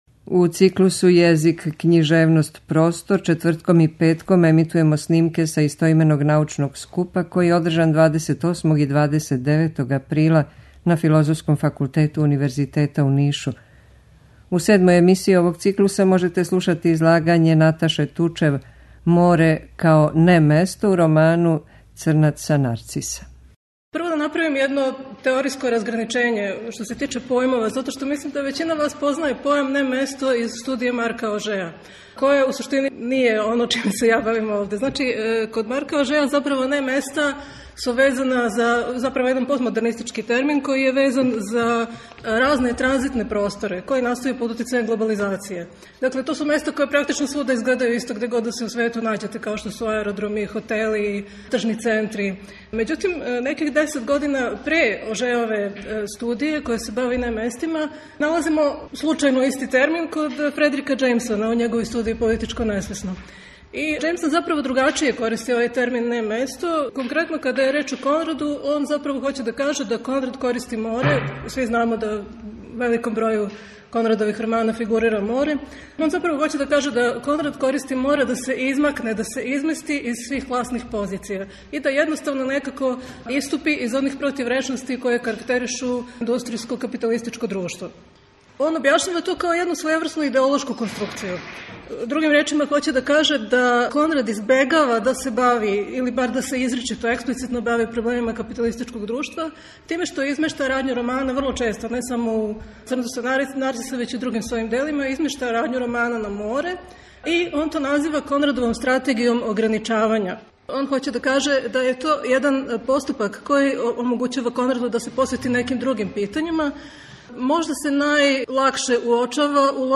У циклусу ЈЕЗИК, КЊИЖЕВНОСТ, ПРОСТОР четвртком и петком ћемо емитовати снимке са истоименог научног скупа, који је одрдржан 28. и 29. априла на Филозофском факултету Универзитета у Нишу.